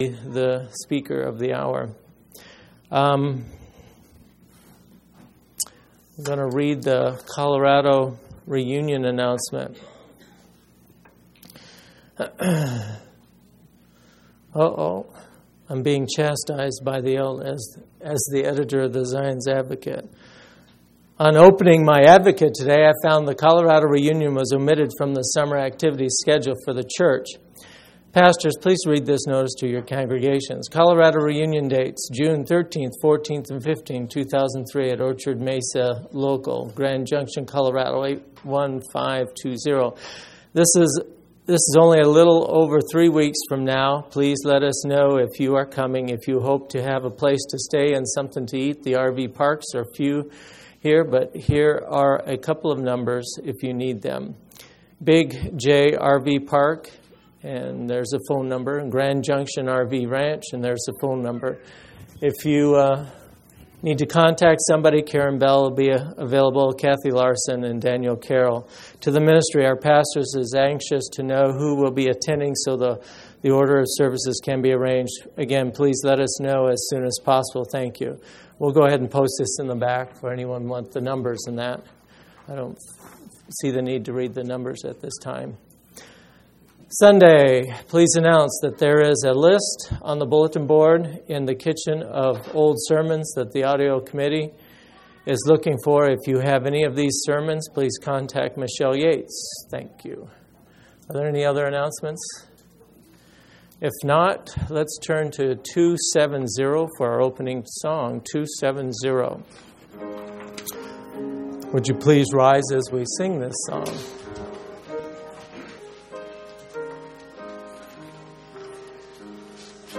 audio-sermons